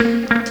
RIFFGTR 11-R.wav